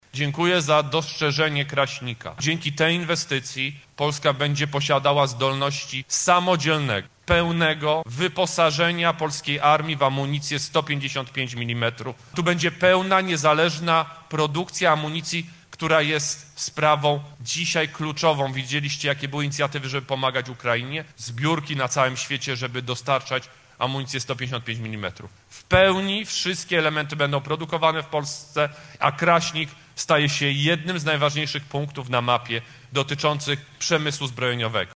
Rekordowe wydatki na obronność, produkcja amunicji w Polsce i kolejne wojskowe inwestycje w regionie- takie zapowiedzi padły z ust wicepremiera i ministra obrony narodowej Władysława Kosiniaka-Kamysza podczas Koncertu Noworocznego PSL w Lublinie. Szef MON podkreślał, że budżet na obronność sięga niemal 200 mld zł, a około 90 proc. zakupów dla Wojska Polskiego będzie realizowanych w krajowych zakładach.
Władysław Kosiniak-Kamysz– mówił Władysław Kosiniak-Kamysz